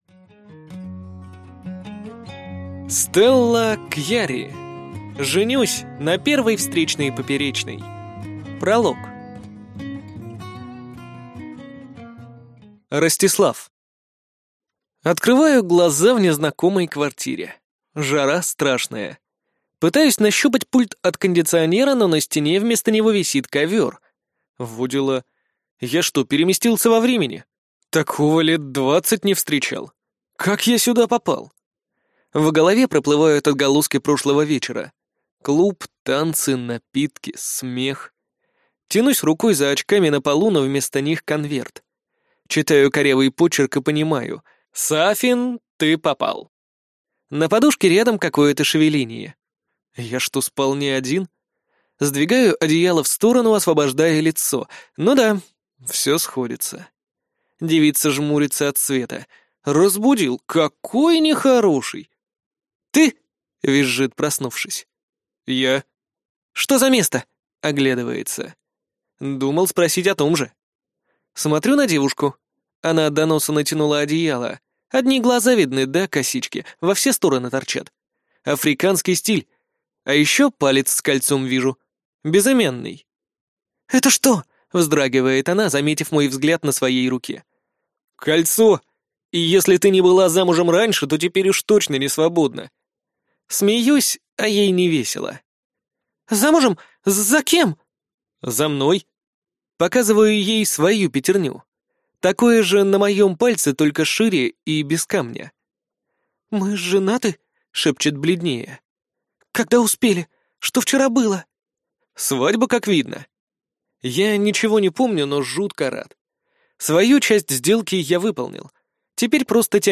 Аудиокнига Женюсь на первой встречной-поперечной | Библиотека аудиокниг